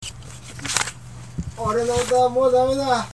こう叫んだ